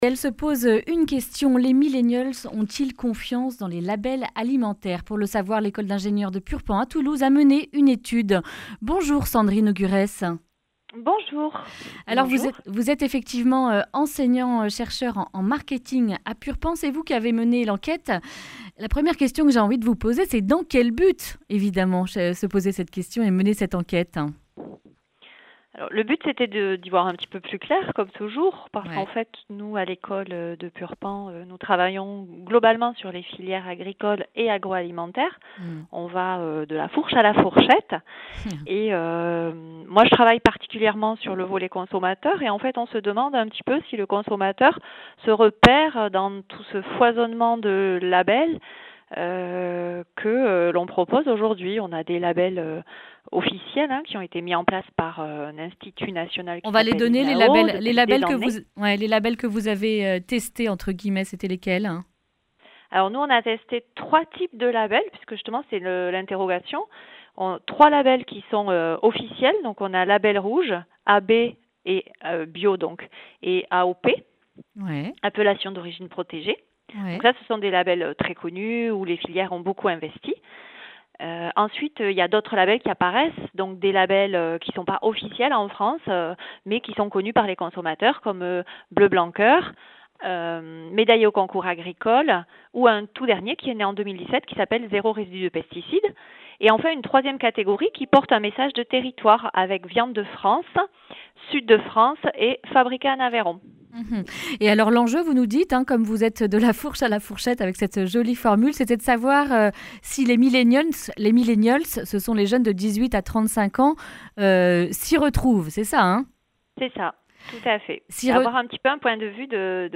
lundi 10 février 2020 Le grand entretien Durée 10 min